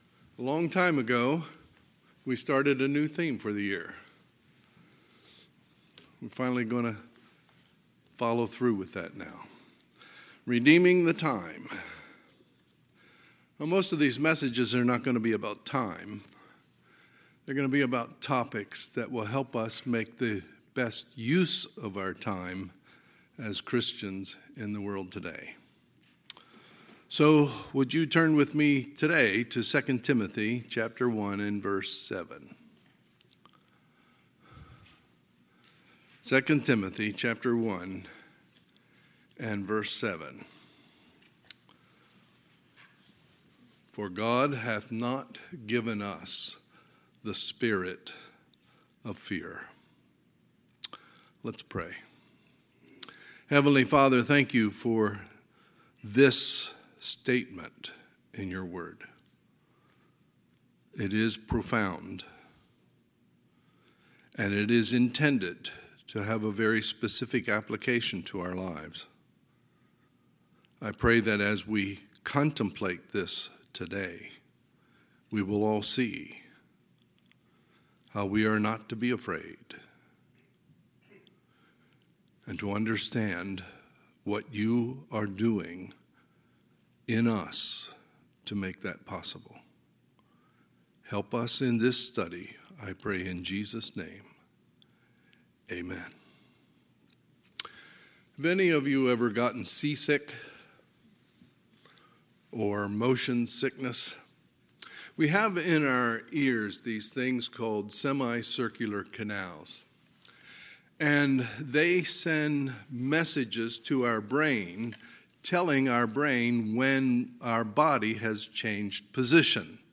Sunday, February 6, 2022 – Sunday AM